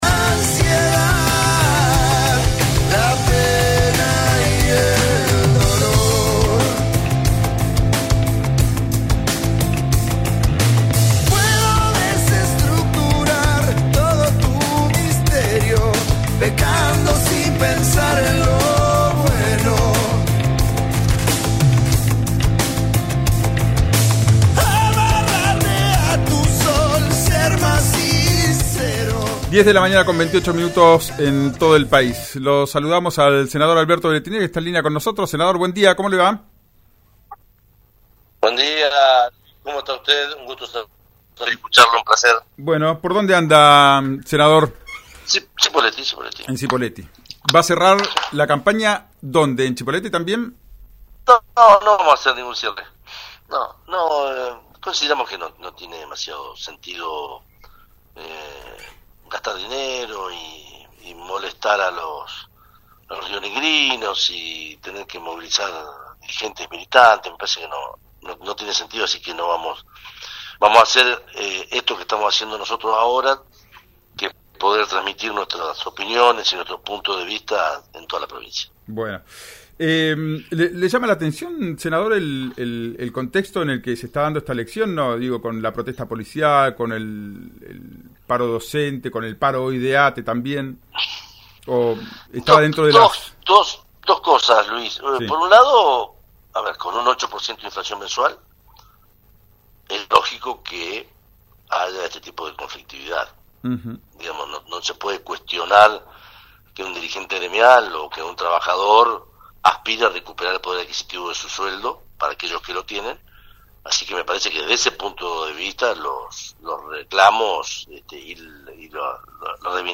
En el programa «Ya es tiempo» de RÍO NEGRO RADIO contó cómo se viven estas últimas horas previas al proceso electoral.
En diálogo con RÍO NEGRO RADIO, el senador aseguró que las protestas de varios sectores cesarán luego de las definiciones tras la elección del domingo porque «cambiará» la motivación de los reclamos gremiales.